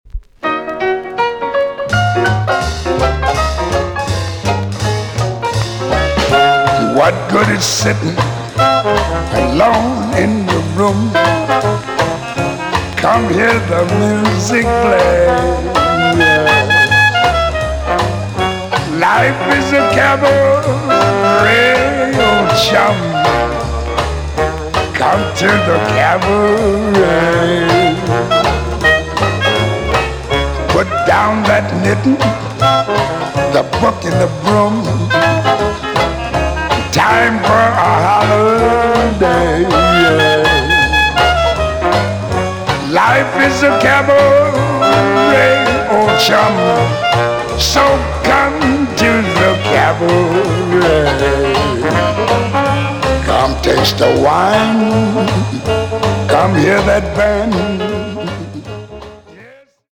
EX- 音はキレイです。